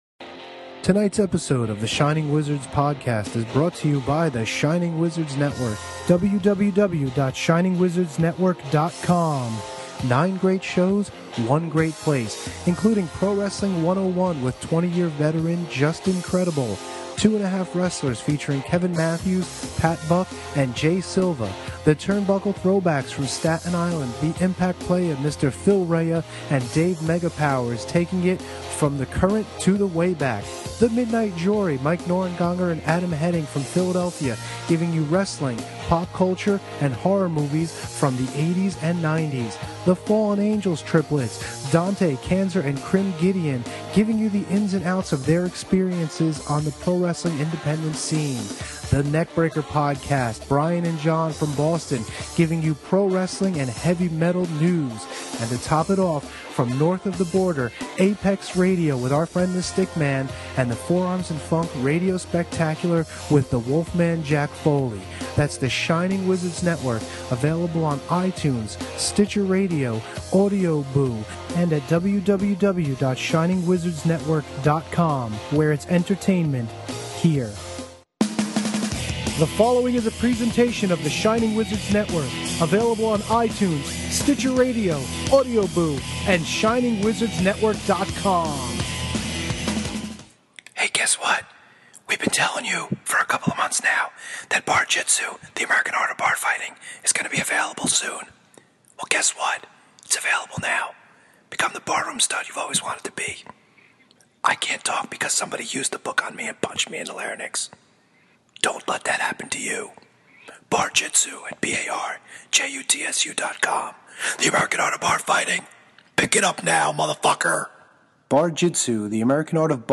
There’s a nor’easter outside, but on the Skype line, it’s nice and toasty.